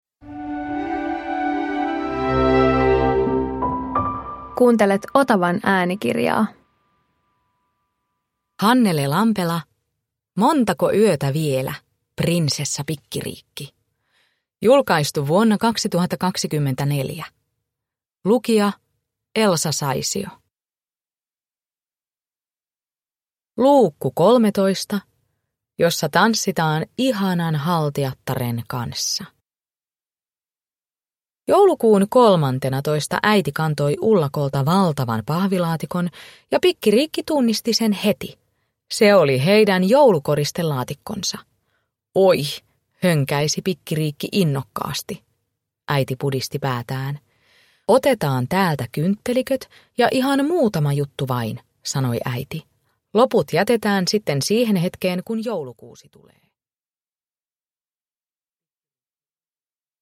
Montako yötä vielä, Prinsessa Pikkiriikki 13 – Ljudbok
Uppläsare: Elsa Saisio